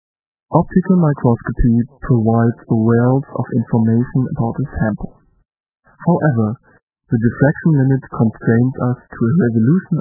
8Kbps speech
sprache_8khz.mp3